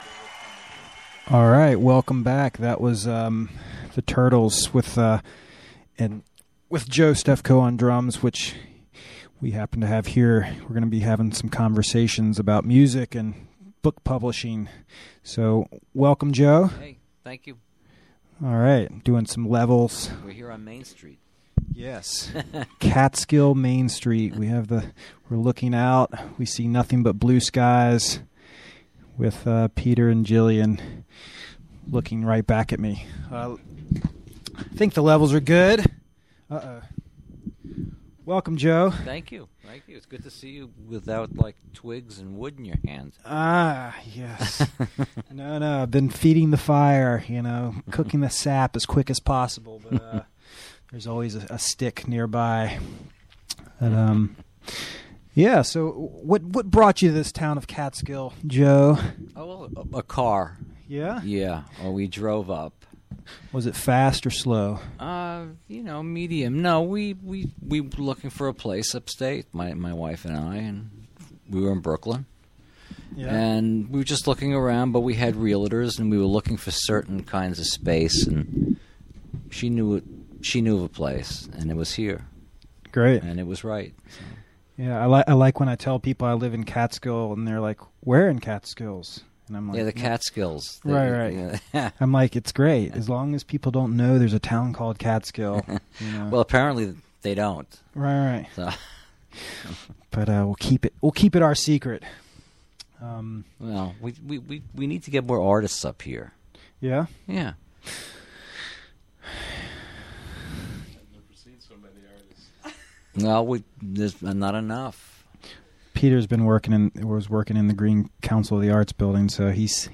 Interview
From "WGXC Afternoon Show" live at Catskill Community Center.